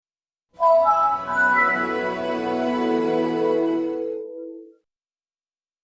windows-vista-start-sound-a.mp3